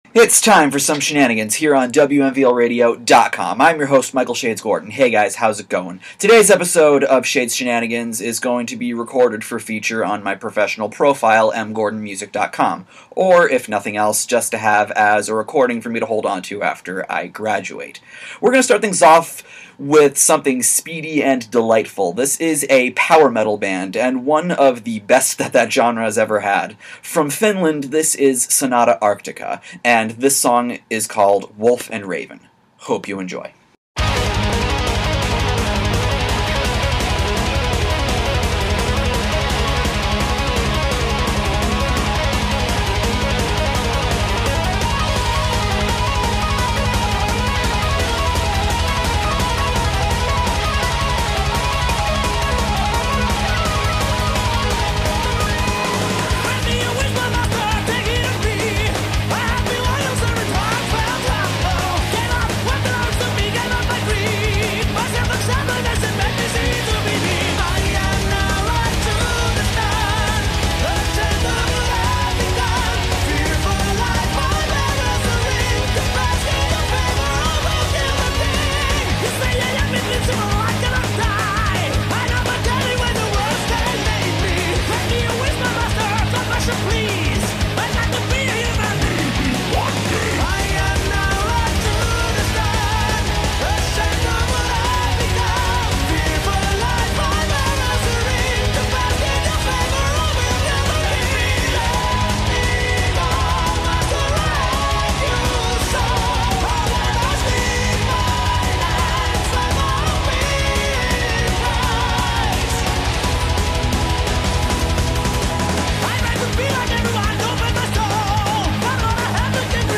FYI, the three rack songs are edited out of the recording, which is why a radio transition sound effect can be heard after the second, fifth and eighth songs.